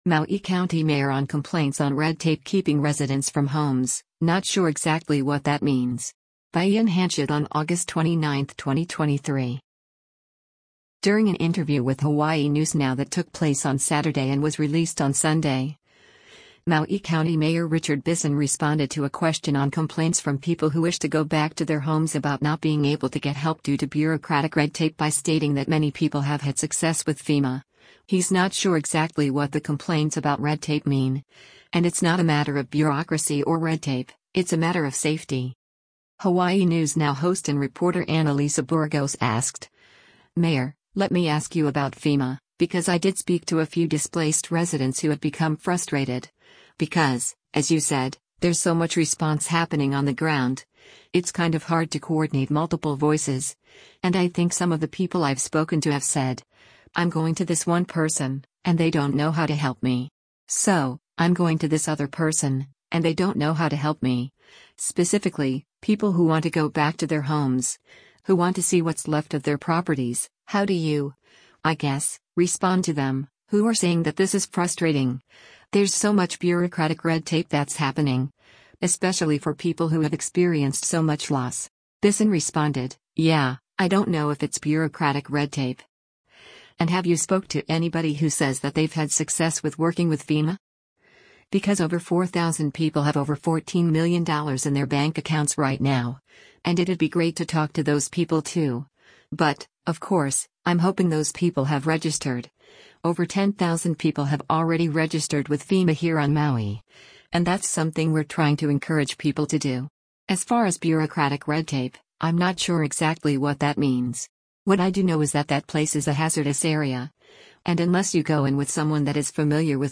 During an interview with Hawaii News Now that took place on Saturday and was released on Sunday, Maui County Mayor Richard Bissen responded to a question on complaints from people who wish to go back to their homes about not being able to get help due to bureaucratic red tape by stating that many people have had success with FEMA, he’s not sure exactly what the complaints about red tape mean, and “it’s not a matter of bureaucracy or red tape, it’s a matter of safety.”